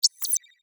Alien Notification 2.wav